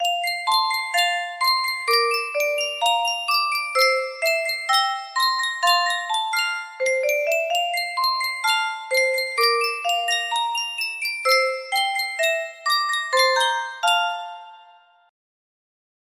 Sankyo Music Box - Jamaica Farewell RZ music box melody
Full range 60